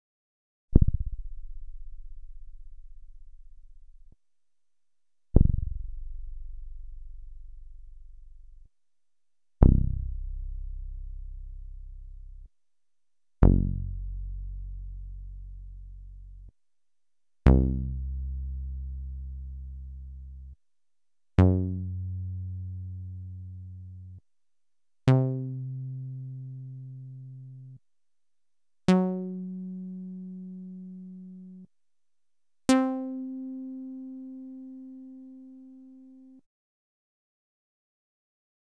02_Bass_5.wav